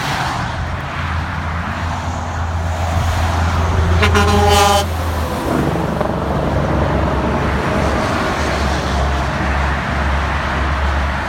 Long Lost Kenworth W900 Jake Sound Effects Free Download